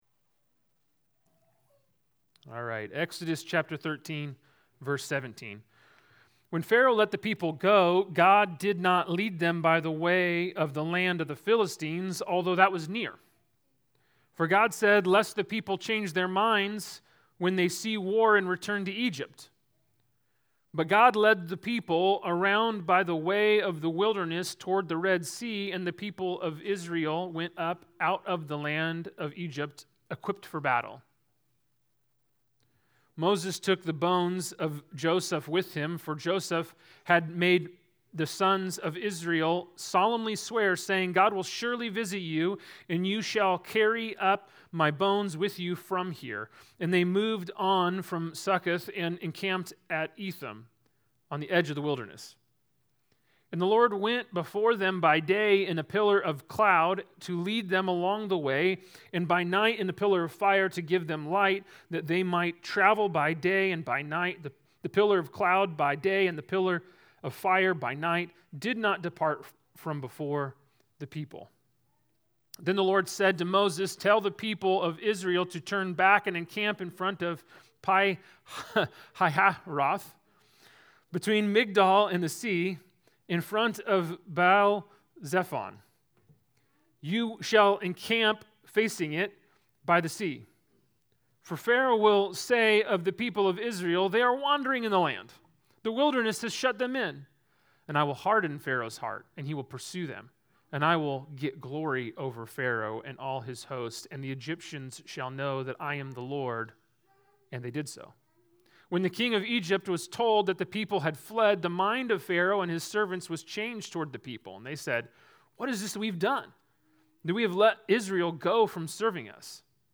Christ Our Passover Proclaim Church Sermons podcast